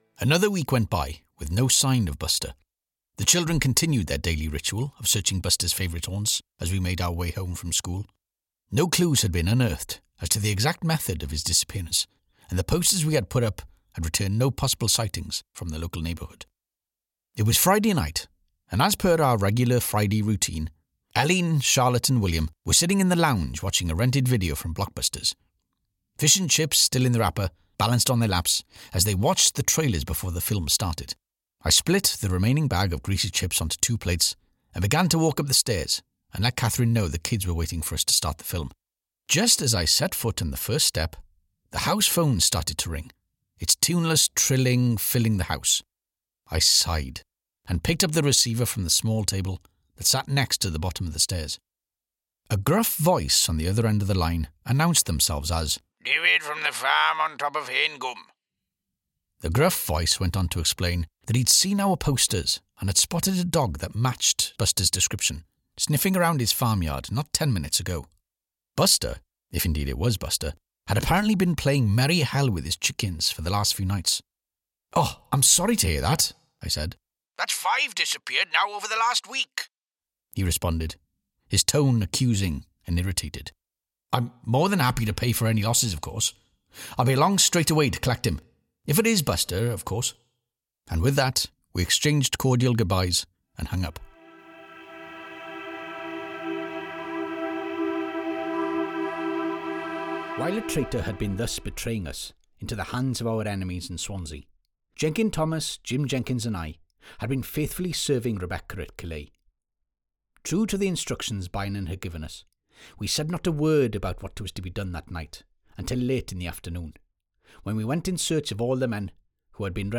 Southern Welsh, Northern Welsh, RP, Liverpool,
Middle Aged
AUDIOBOOK VOICEREEL.mp3